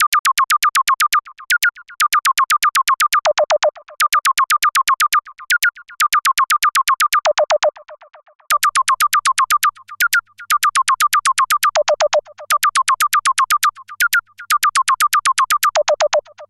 Второй фрагмент- квадрат Korg Odyssey (VST) и квадрат Ulyssey. Думаю, что характер резонанса вполне схож, а огибаи иначе работают.